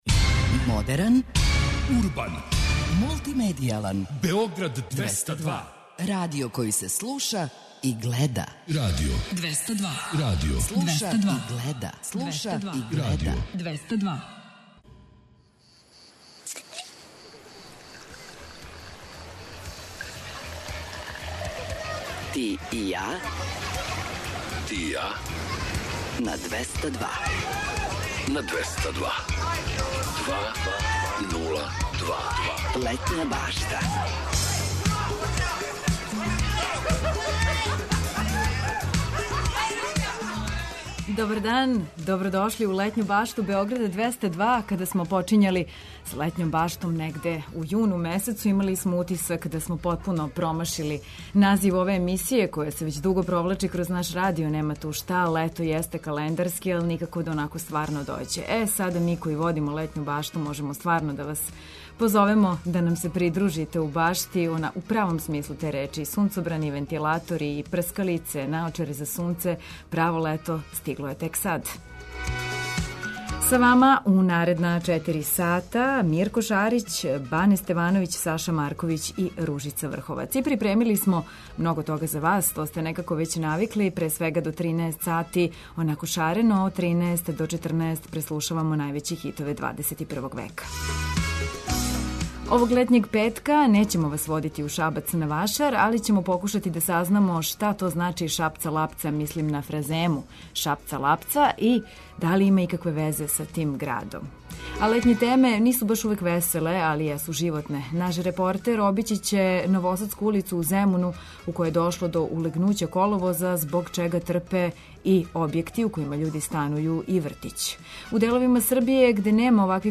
Наш репортер обићи ће Новоградску улицу у Земуну, у којој је дошло до улегнућа коловоза, тротоара и паркинга, а значајно су оштећени објекти, куће и вртић.